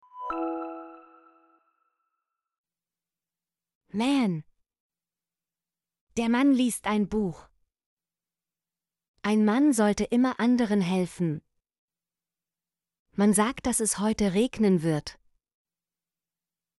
mann - Example Sentences & Pronunciation, German Frequency List